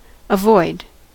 avoid: Wikimedia Commons US English Pronunciations
En-us-avoid.WAV